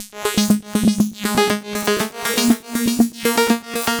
Prog Chomp Ab 120.wav